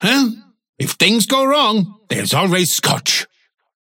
Kelvin voice line - Well, if things go wrong, there's always Scotch!
Kelvin_concerned_06.mp3